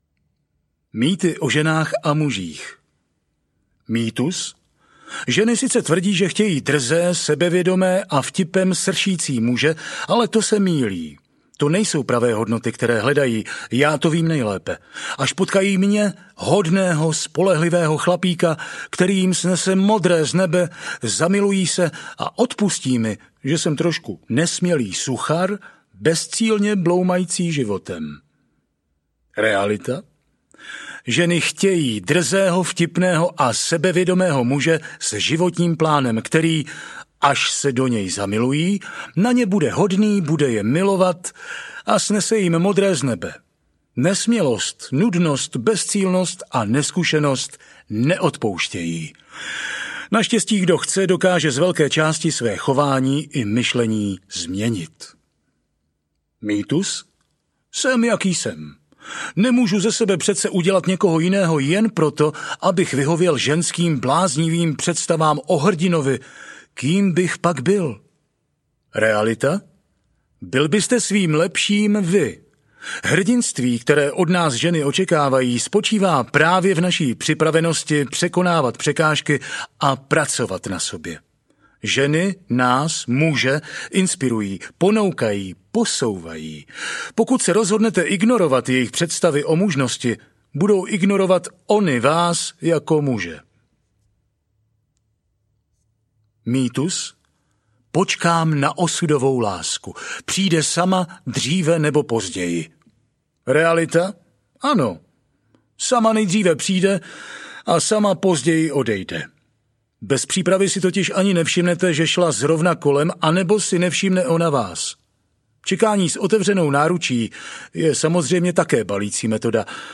Audiokniha Jak sbalit ženu 2.0 - Tomáš Baránek | ProgresGuru